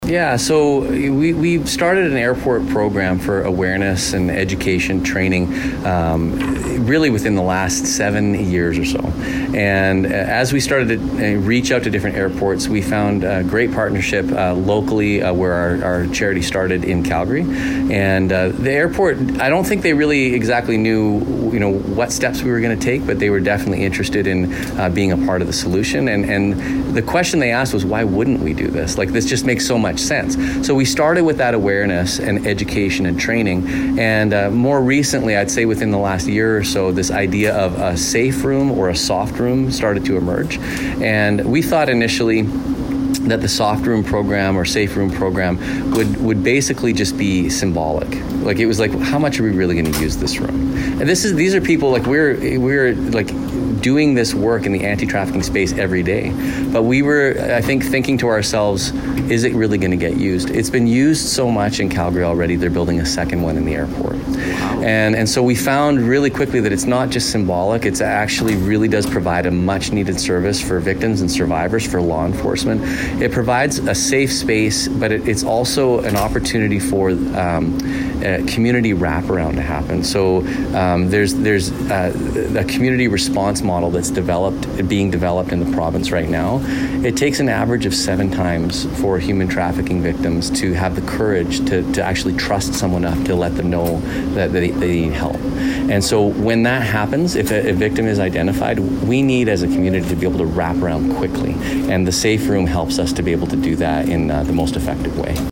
Brandt talks about how the saferoom collaboration with the airport and why it’s important.